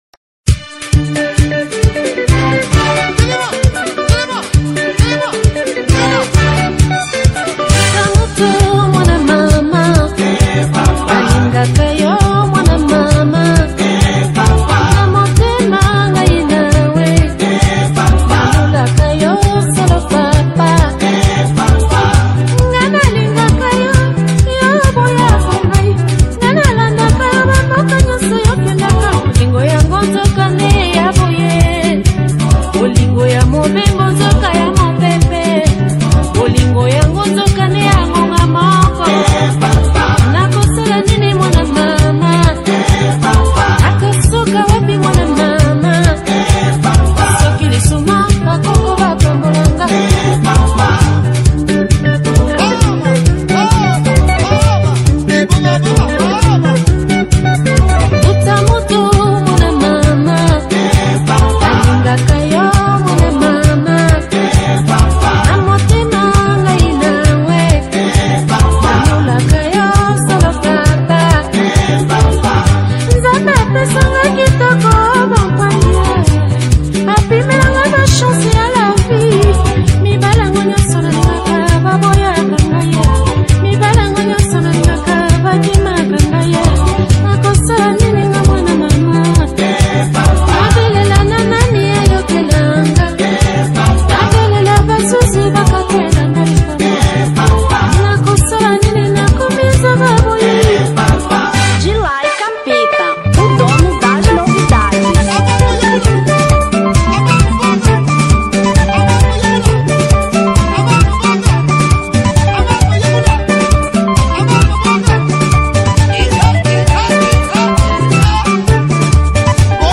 Rumba 1993